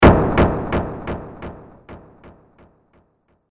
PALPITAR PALPITAR
Ambient sound effects
palpitar_PALPITAR.mp3